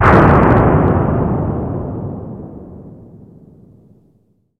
explo2.wav